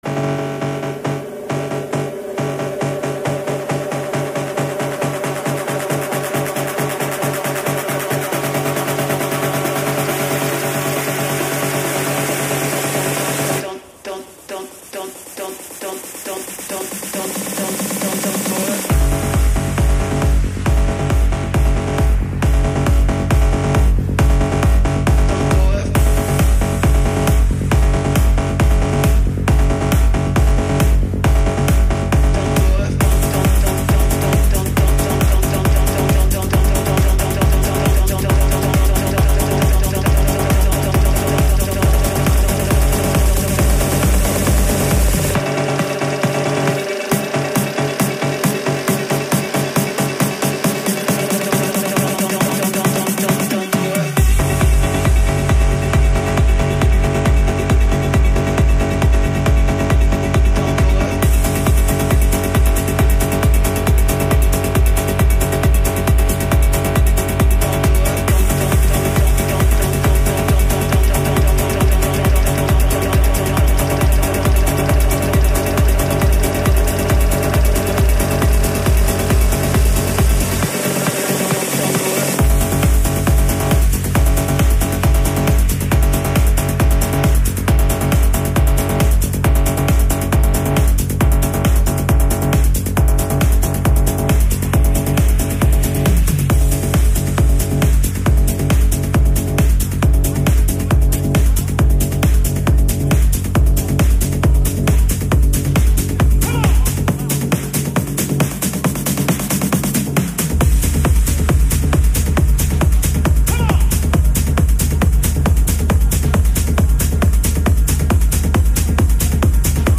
Also find other EDM